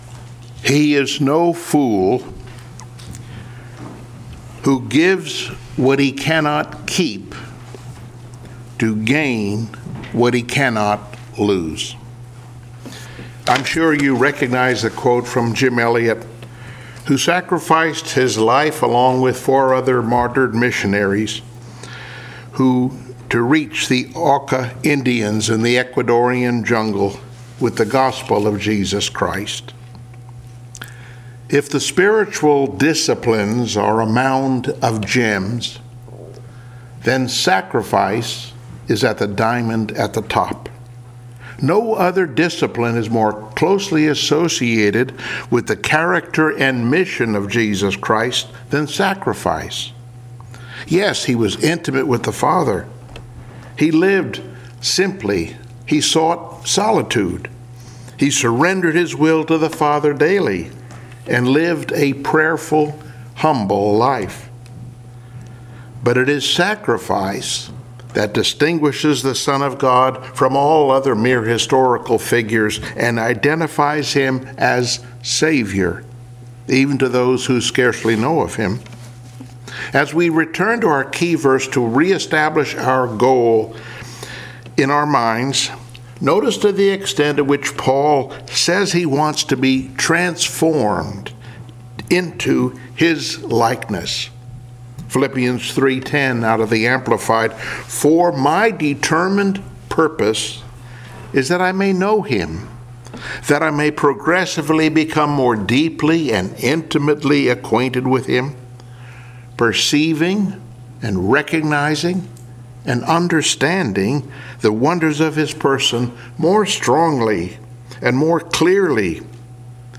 Passage: Romans 12:1 Service Type: Sunday Morning Worship Topics